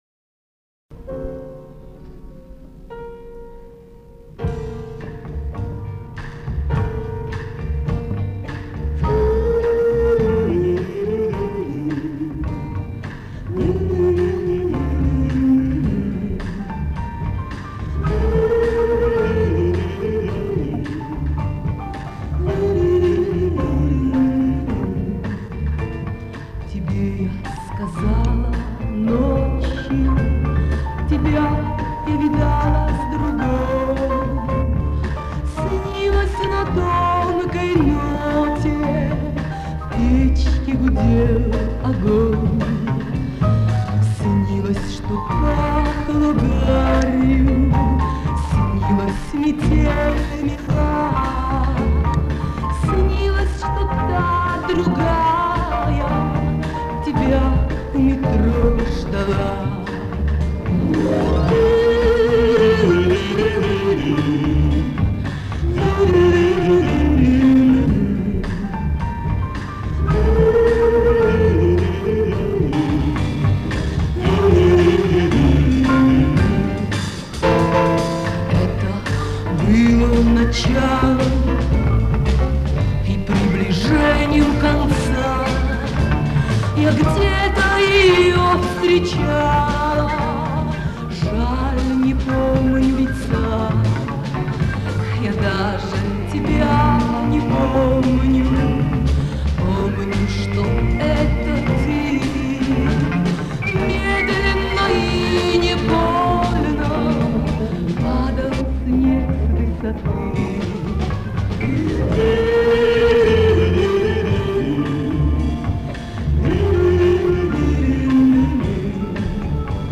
Все эти ленты из архива радиостанции Маяк.